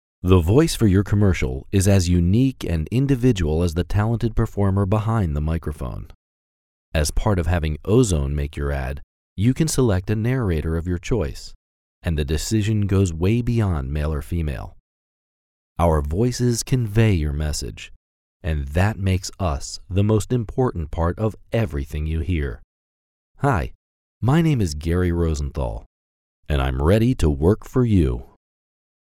A Voice For Video